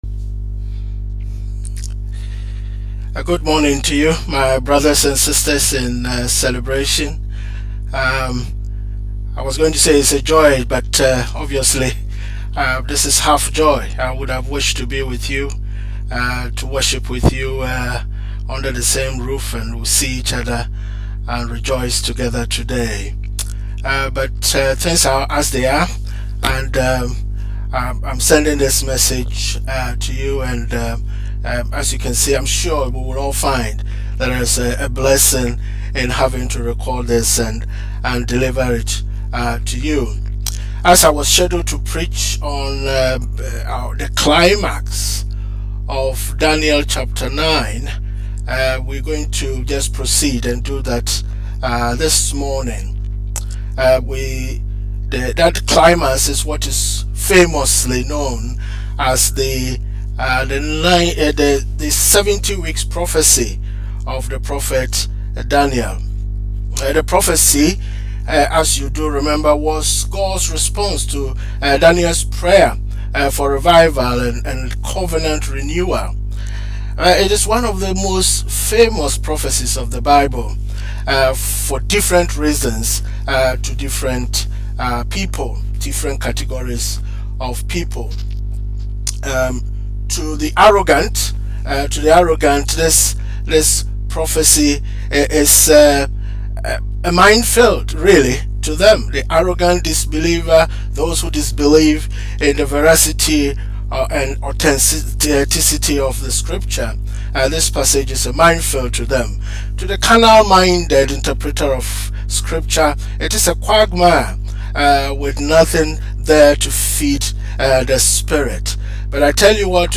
Welcome to our online ‘service’.